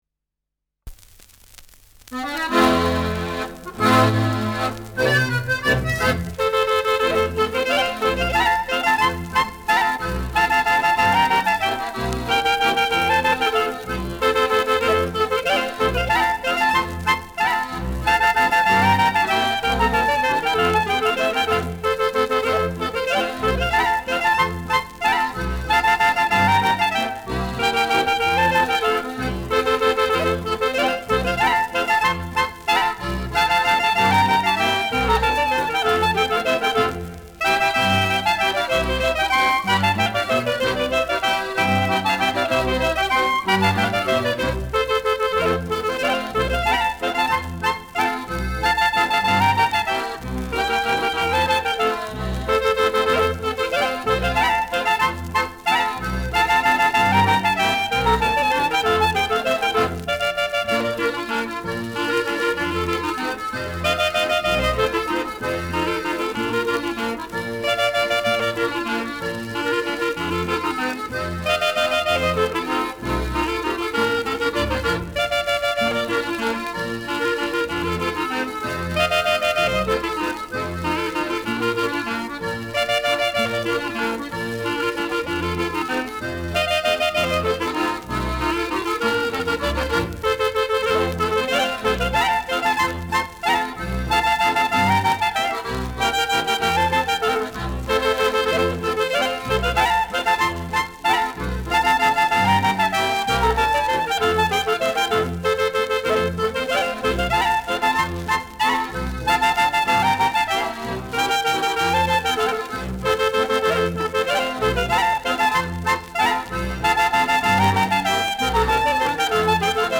Schellackplatte
Tonrille: Abrieb : graue Rillen : leichte Kratzer
Bischofshofener Tanzlbuam (Interpretation)
Auf dieser Aufnahme erklingt ein Ländler und nicht eine Polka, wie auf dem Etikett angekündigt.
Folkloregruppe* FVS-00013